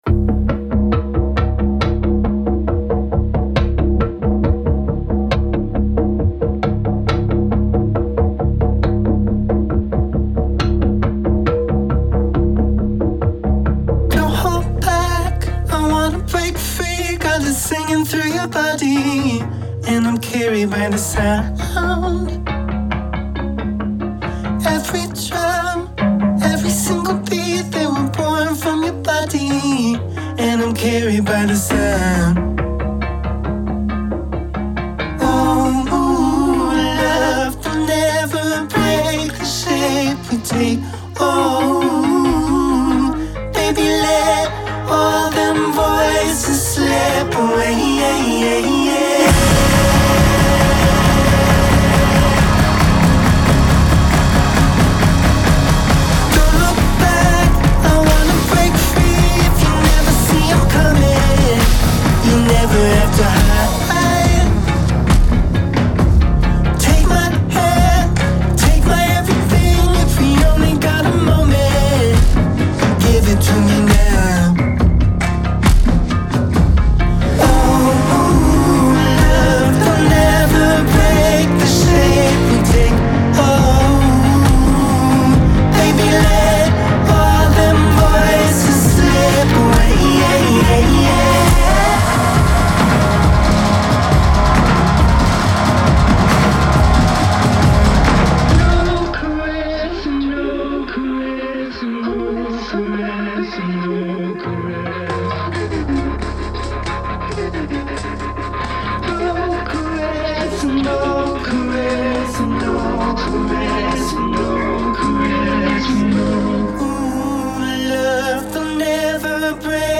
cosmically expansive, baroque declaration of love